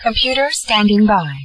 Computersounds & Gadgets
(female Computervoice says: "Computer standing by")
computerstandingby.wav